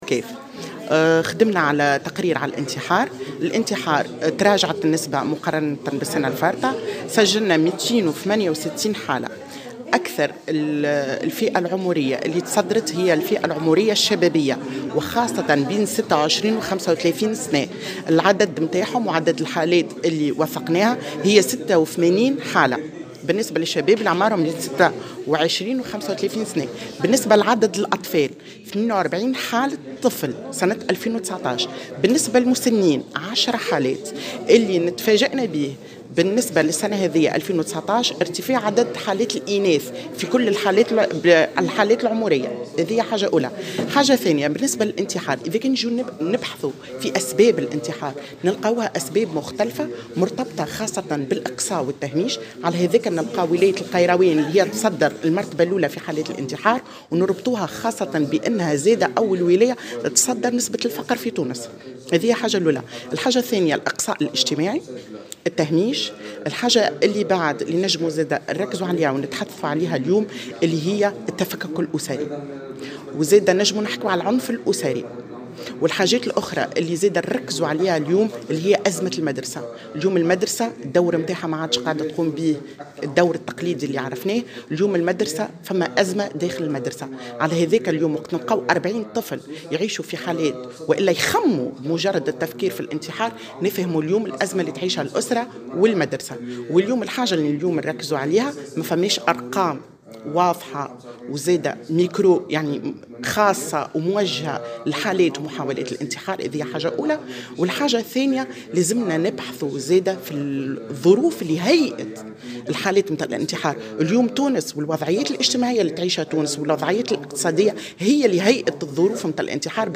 في تصريح لمراسل الجوهرة "اف ام"